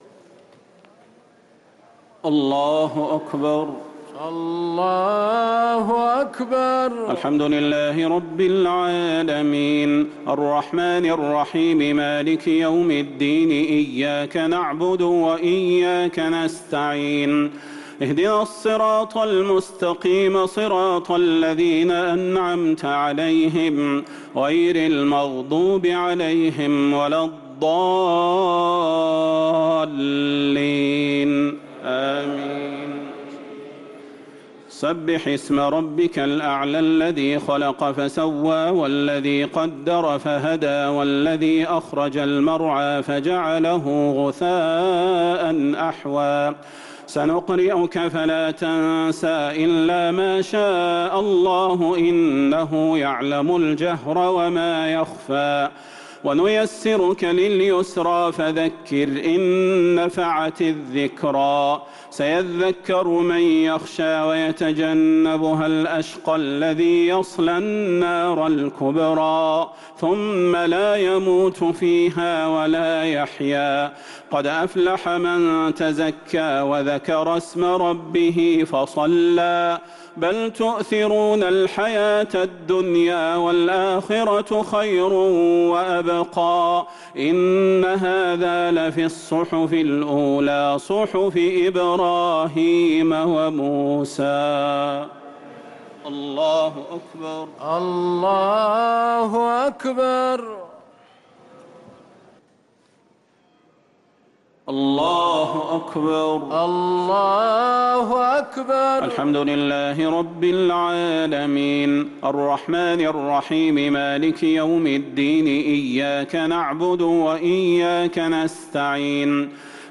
الشفع و الوتر ليلة 17 رمضان 1444هـ | Witr 17 st night Ramadan 1444H > تراويح الحرم النبوي عام 1444 🕌 > التراويح - تلاوات الحرمين